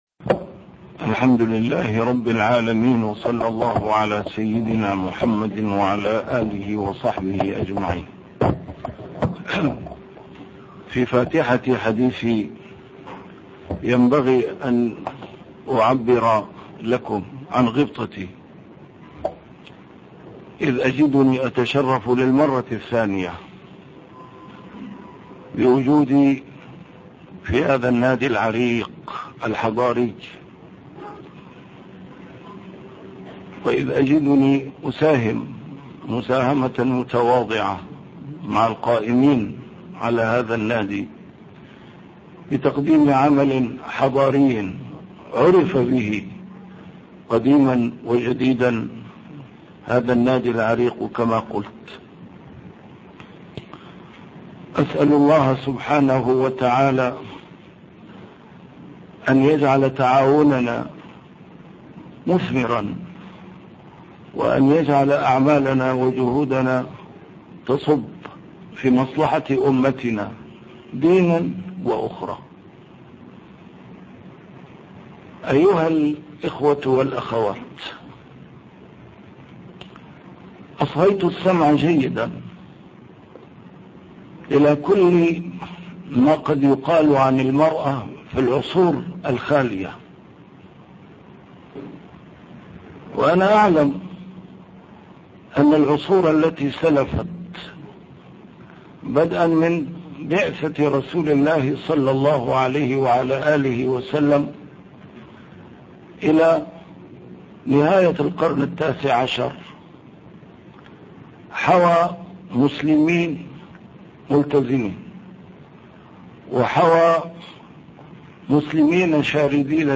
محاضرات متفرقة في مناسبات مختلفة - A MARTYR SCHOLAR: IMAM MUHAMMAD SAEED RAMADAN AL-BOUTI - الدروس العلمية - ما يقال عن الإسلام والمرأة في هذا العصر